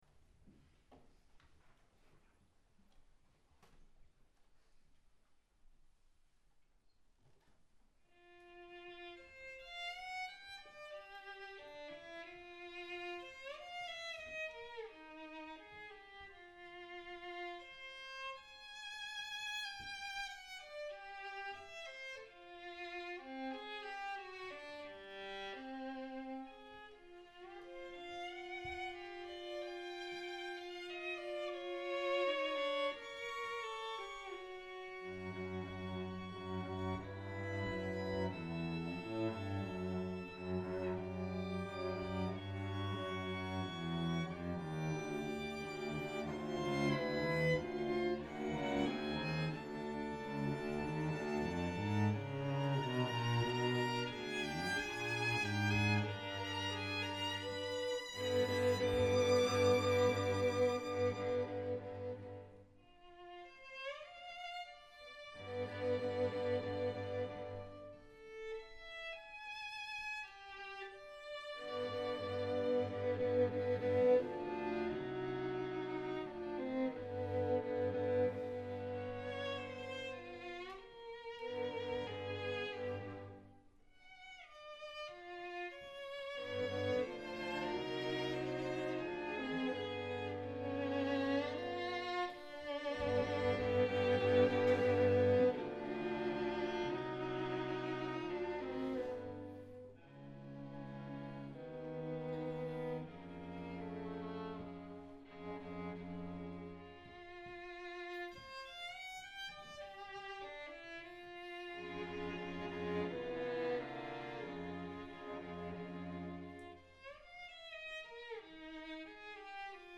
Venue: Bantry Library